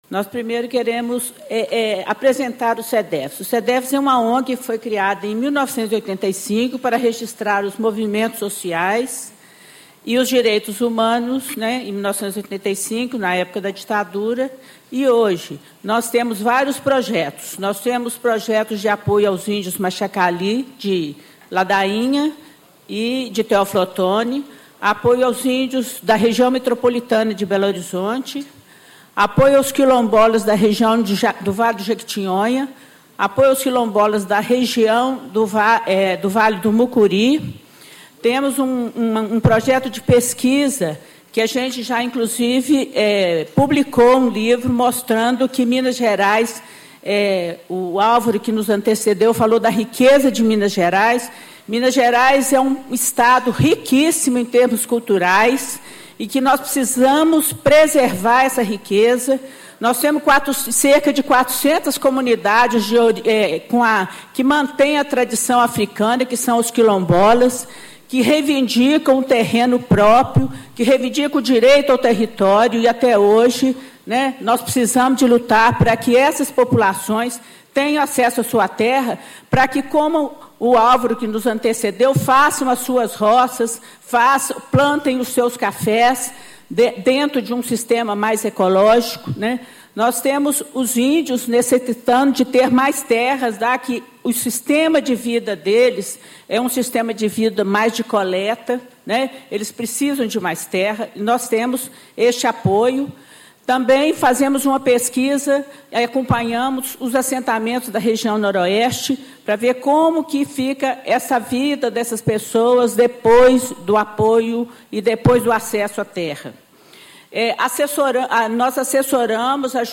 Discursos e Palestras Rumo à Rio + 20 e à Cúpula dos Povos